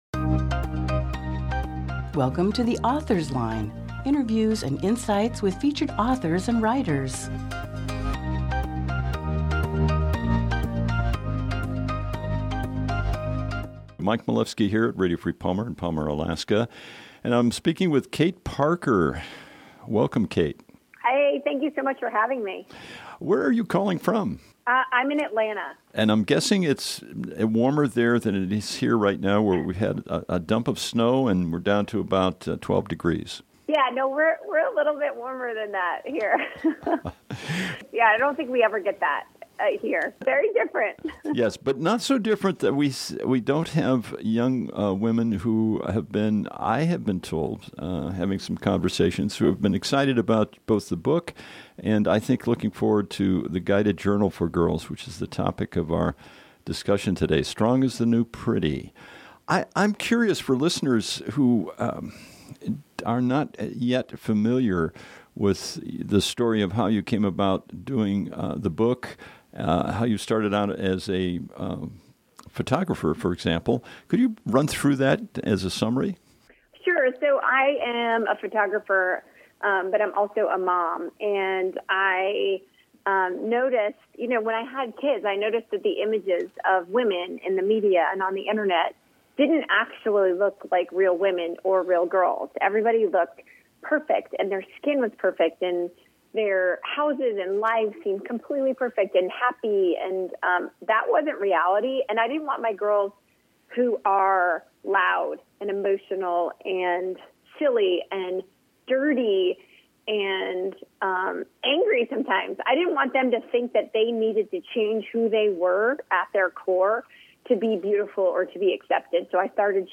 Nov 3, 2018 | Author Interviews